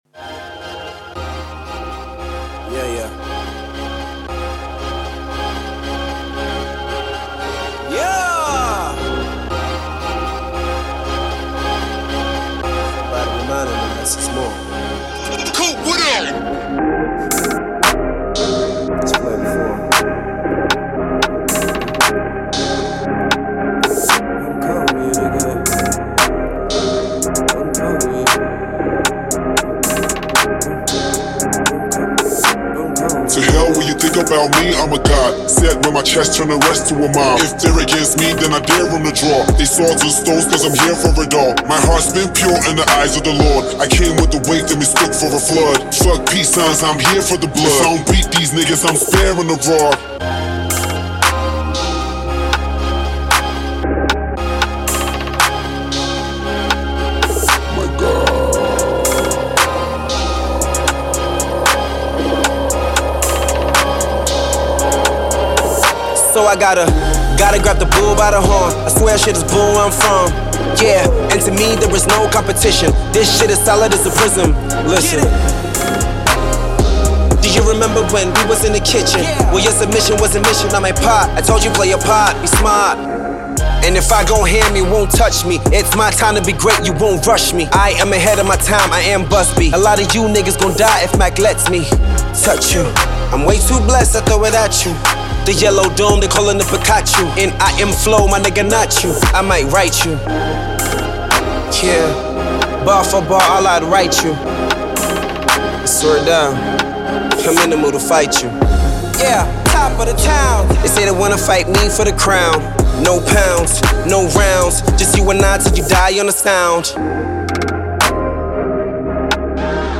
• Genre: Dancehall